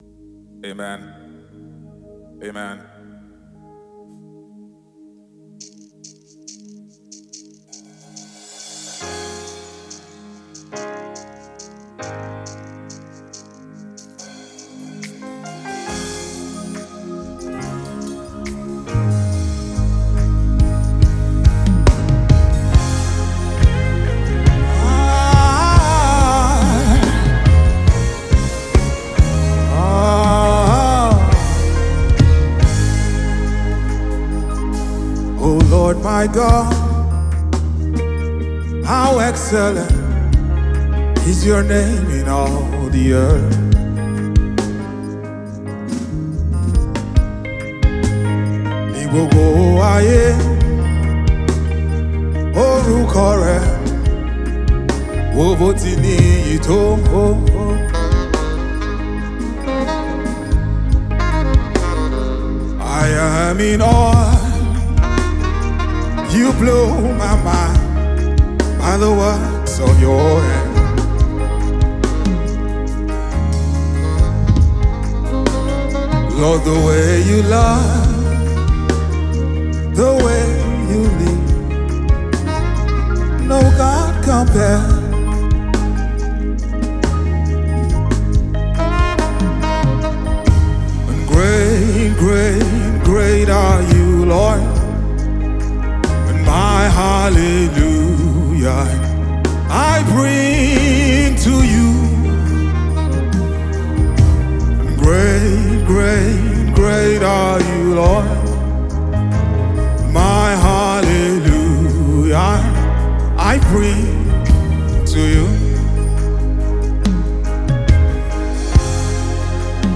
Check out the new tune from Gospel musician